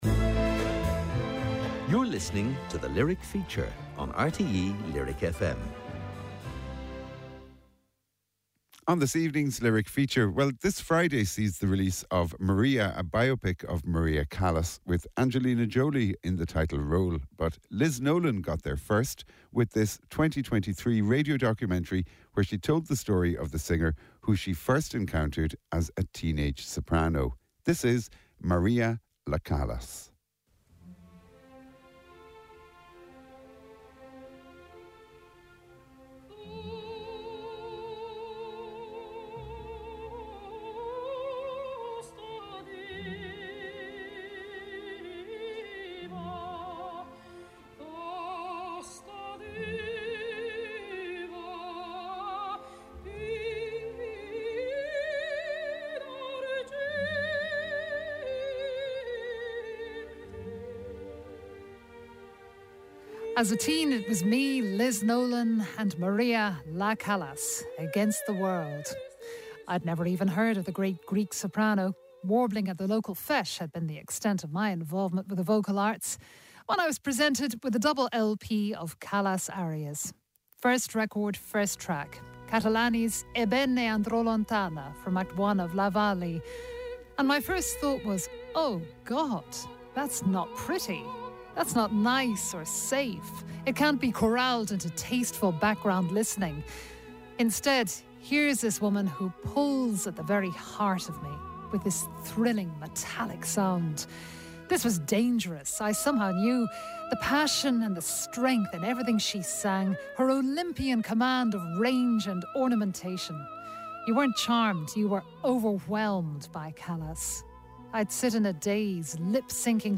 Irish broadcaster RTÉ lyric fm's weekly documentary slot. Programmes about music, literature, visual arts and other areas where creativity is manifest.